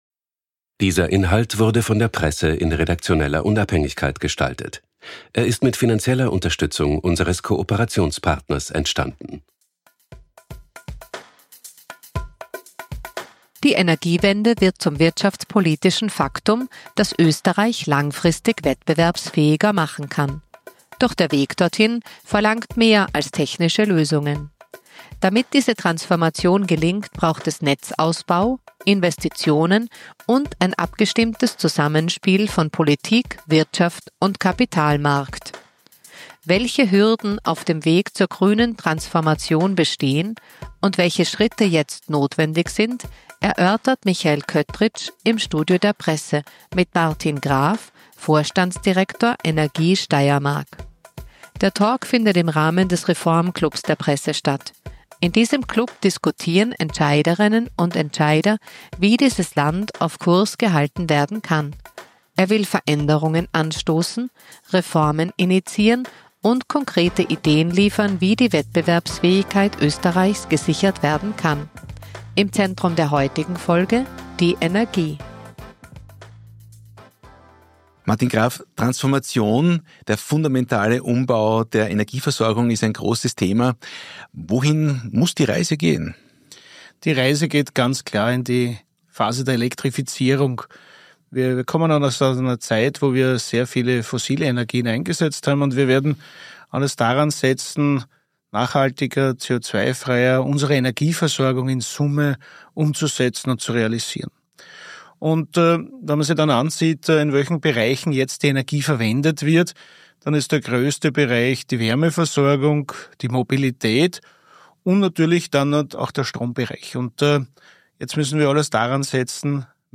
im Gespräch mit „Die Presse“.